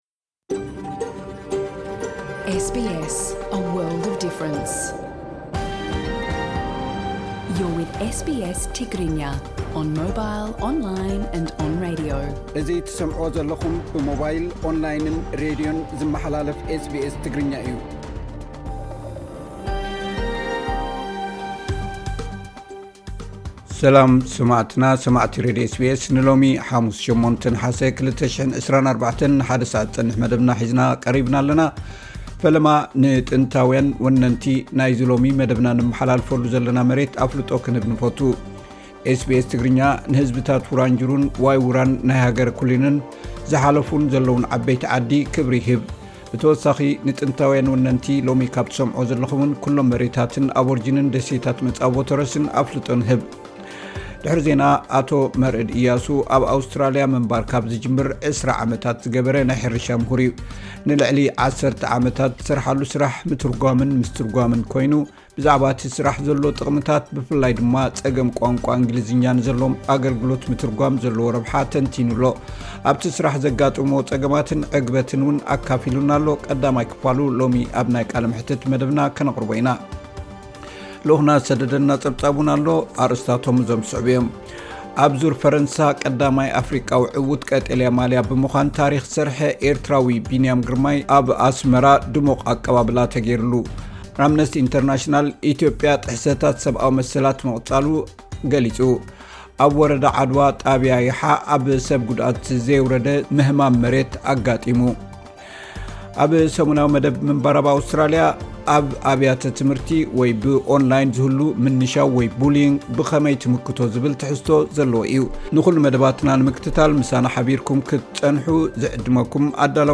ዕለታዊ ዜና ኤስ ቢ ኤስ ትግርኛ (08 ነሓሰ 2024)